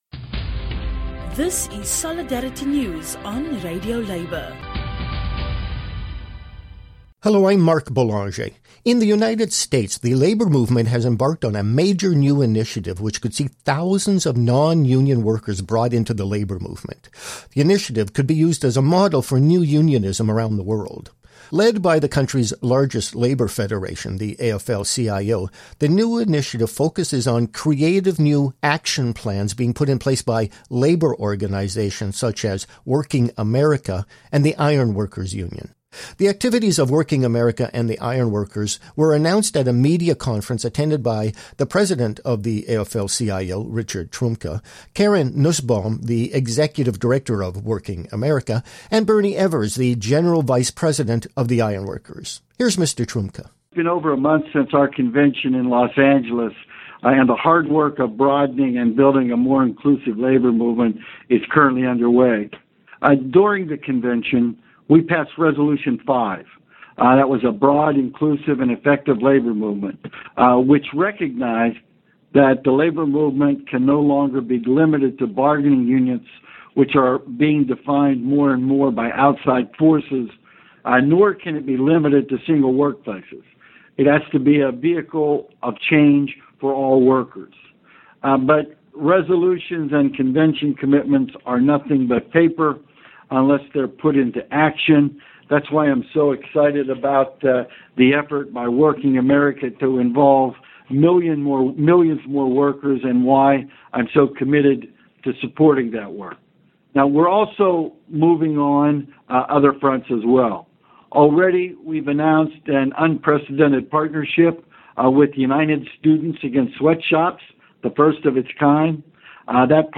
Comments by the President of the AFL-CIO, Richard Trumka.